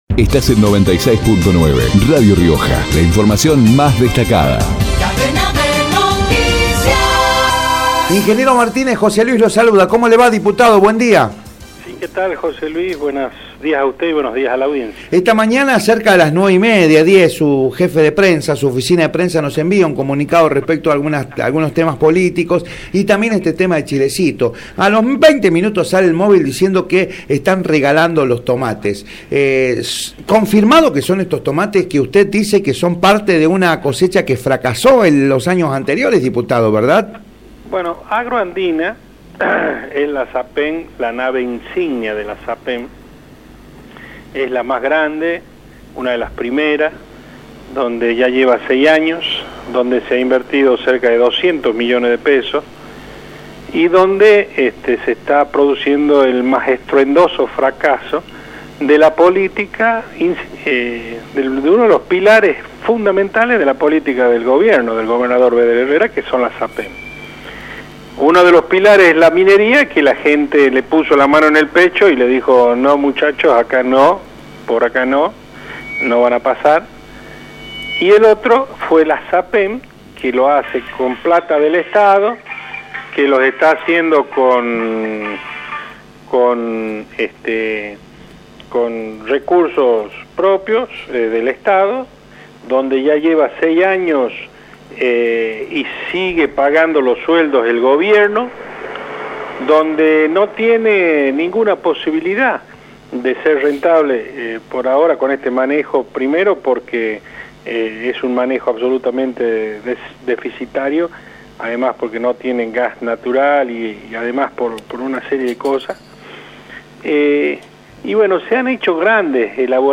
Julio Martínez, diputado nacional, por Radio Rioja